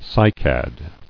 [cy·cad]